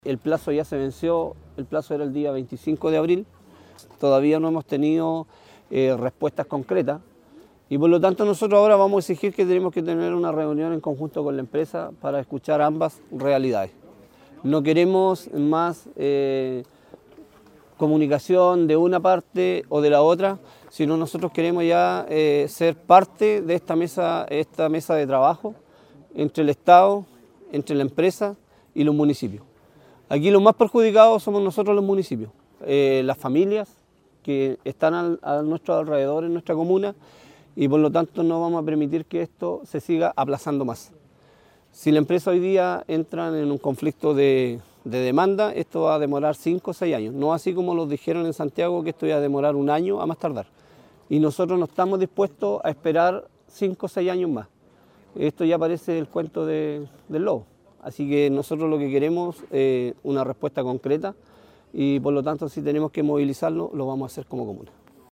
Carlos Toloza, alcalde de Nacimiento y anfitrión de este encuentro, declaró que “vamos a exigir una reunión en conjunto con la empresa para escuchar ambas realidades. Queremos ser parte de la mesa de trabajo entre el estado, la empresa y los municipios”.